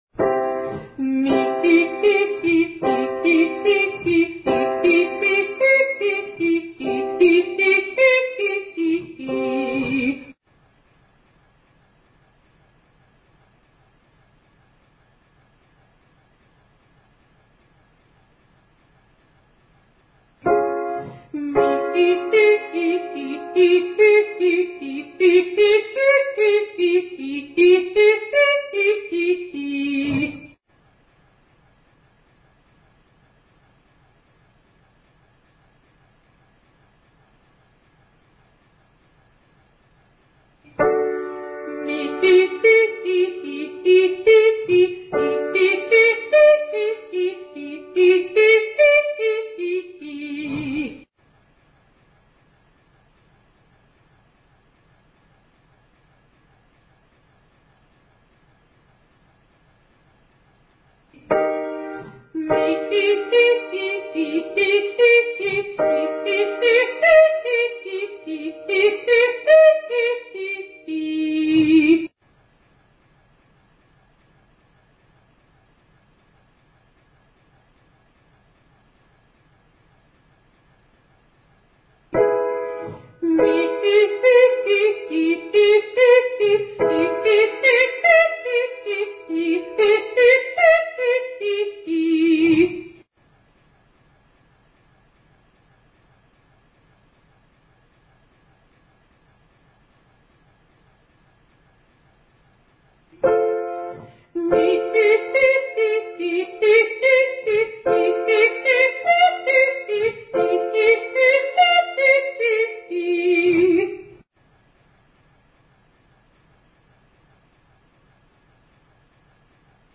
342: Triad Octave Arpeggio to high g on /mee hee/
Vft-1342-Triad-Octave-Arpeggio-To-High-G.mp3